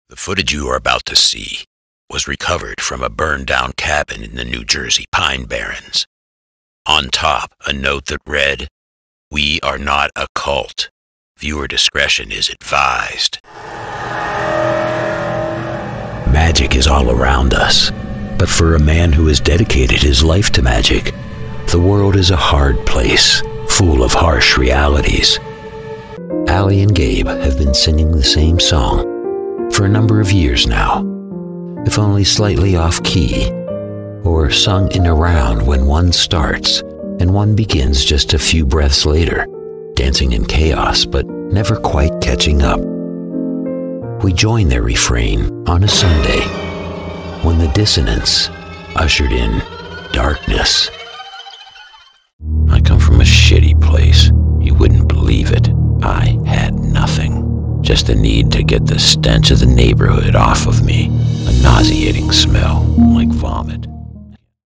Male
Authoritative, Character, Confident, Cool, Corporate, Deep, Engaging, Friendly, Gravitas, Posh, Reassuring, Smooth, Streetwise, Warm, Versatile, Conversational, Funny, Sarcastic, Assured, Upbeat
A voice like smooth gravel—deep, textured, and riveting.
Audio equipment: Pro, acoustically treated studio with Source-Connect, Grace m101 preamp, Audient iD4 interface